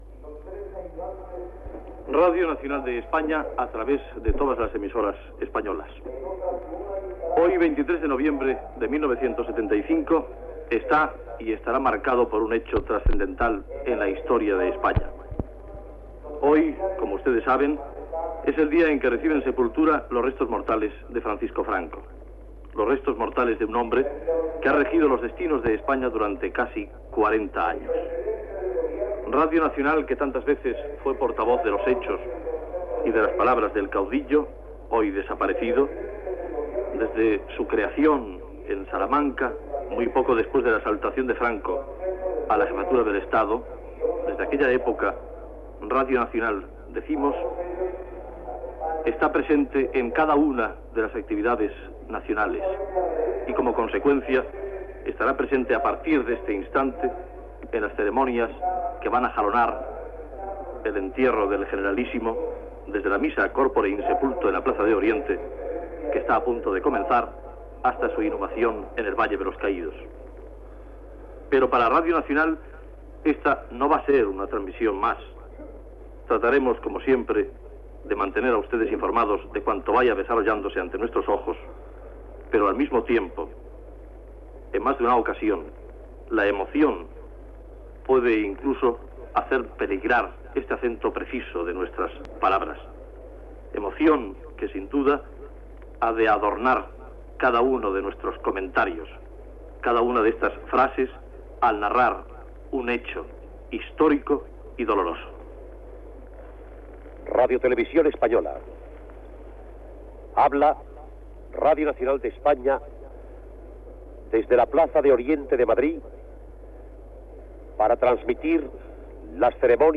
Identificació, data, inici de la transmissió de la missa "corpore in sepulto" del "Generalísimo" Franco, a la Plaza de Oriente de Madrid
Informatiu